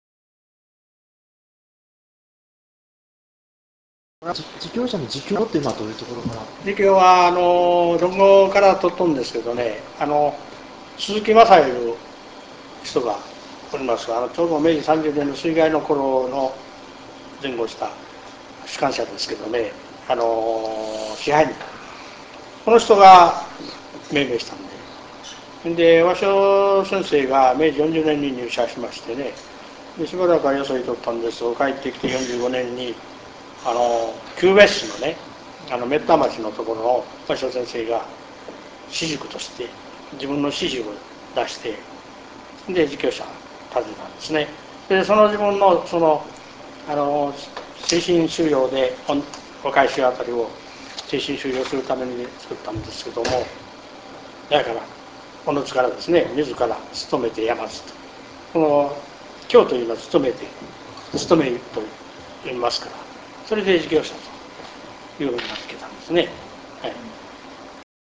インタビュー ビデオをご覧いただくには、RealPlayerソフトが必要です。
平成１２年（２０００）１１月２９日　本校にて収録　   　自彊舎の変遷、